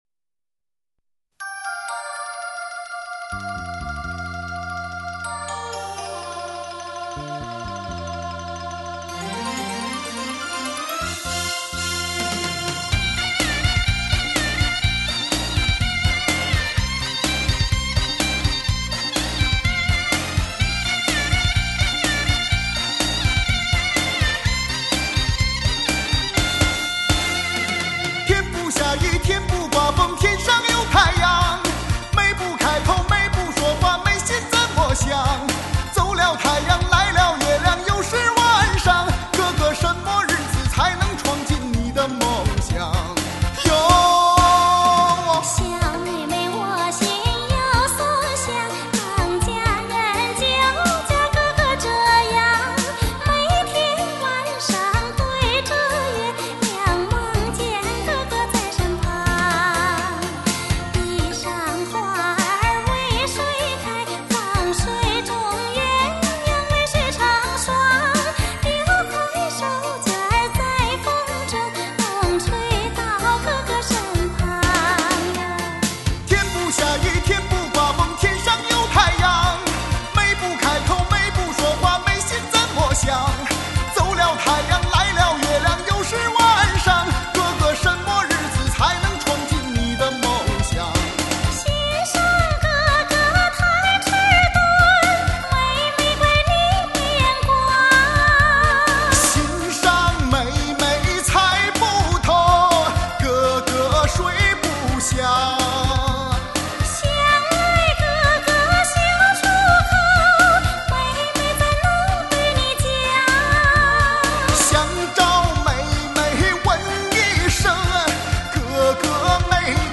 平四
爱好交谊舞的朋友 请随着舞曲音乐翩翩起舞